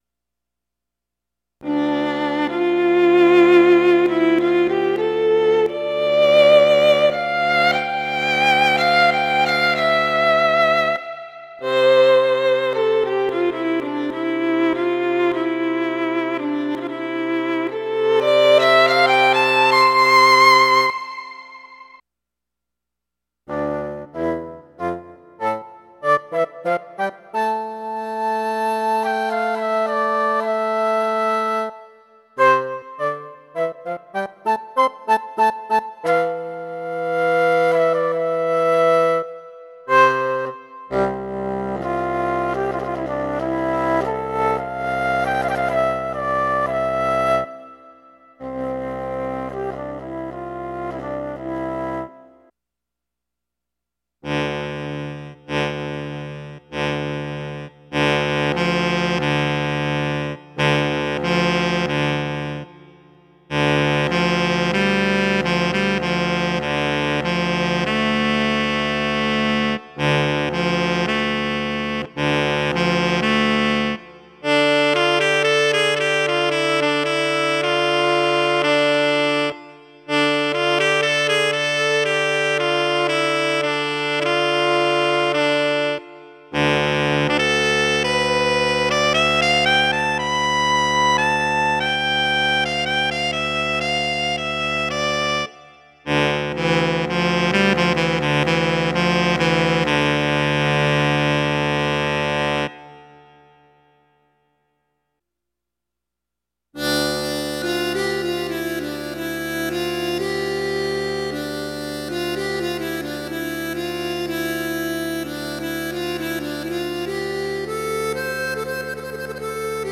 Some noodling using the the Electra One to do some EWI style holds over two MIDI channels on my XPresso to get two different timbres going at once.